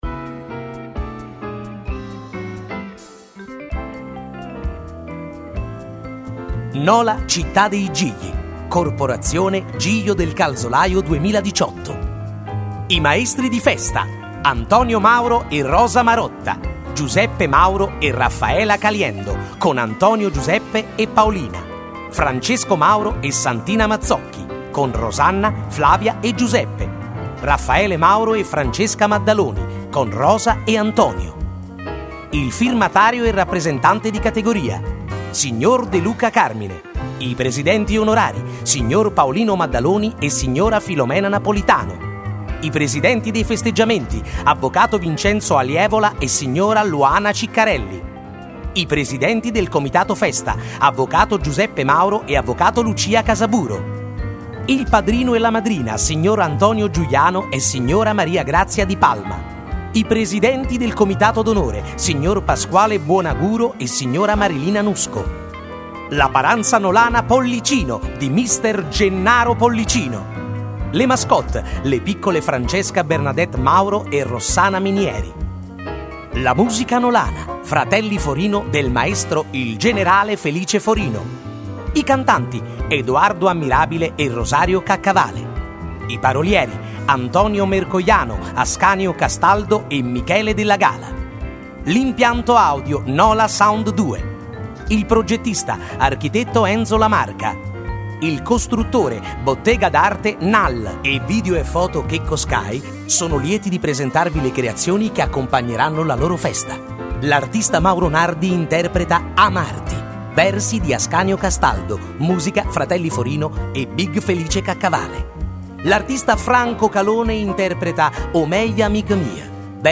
Presentazione